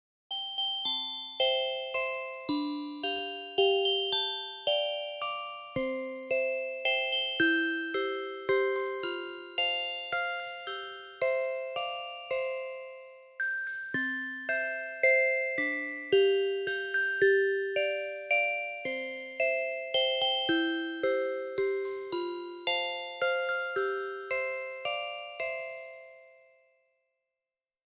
Klasyczny urodzinowy dzwonek.